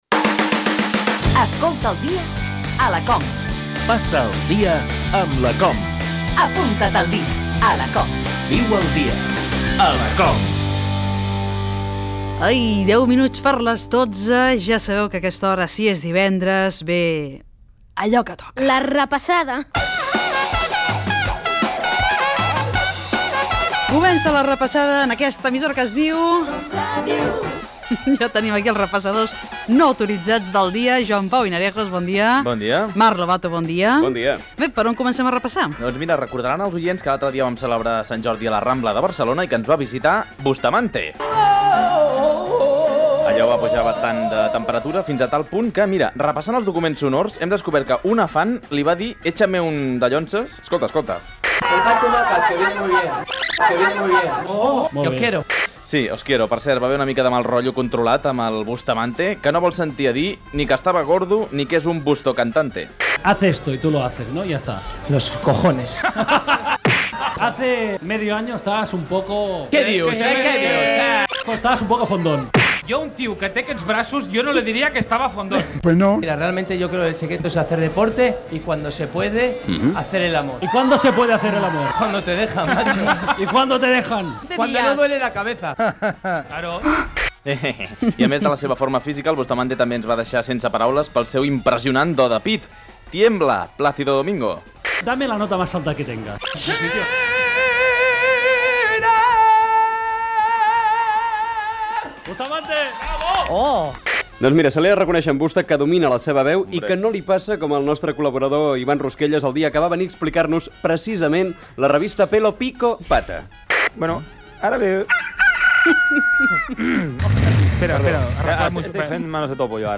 Indicatiu del programa
Info-entreteniment
Fragment extret de l'arxiu sonor de COM Ràdio.